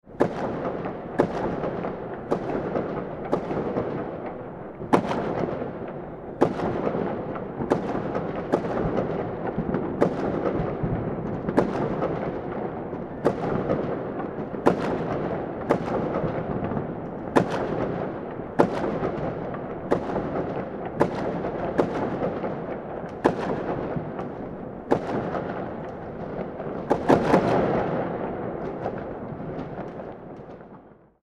4th Of July Celebration Sound Effect
Capture the authentic sound of a lively street celebration with this high-quality fireworks explosion audio.
Enhance your videos, games, and multimedia projects with realistic booms, crackles, and bursts from a true pyrotechnic display.
4th-of-july-celebration-sound-effect.mp3